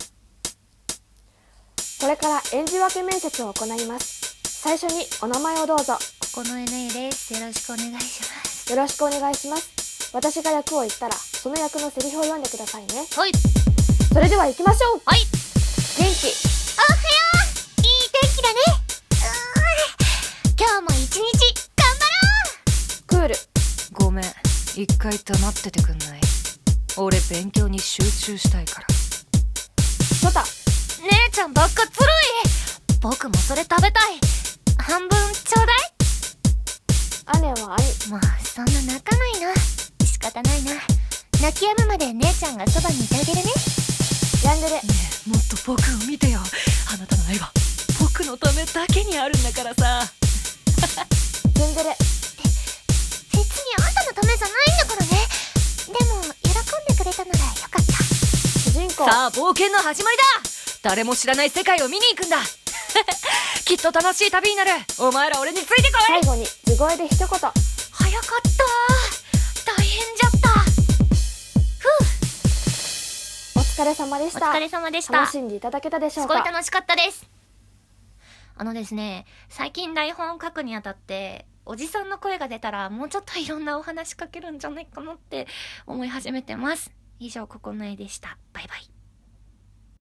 7役演じ分け声面接!!